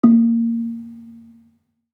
Gambang-A2-f.wav